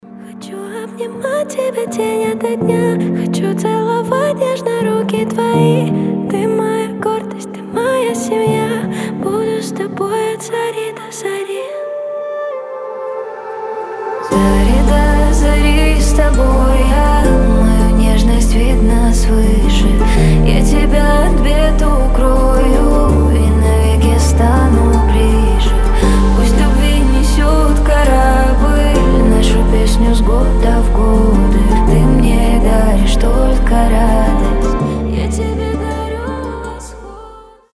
• Качество: 320, Stereo
красивый женский вокал
нежные
Нежное исполнение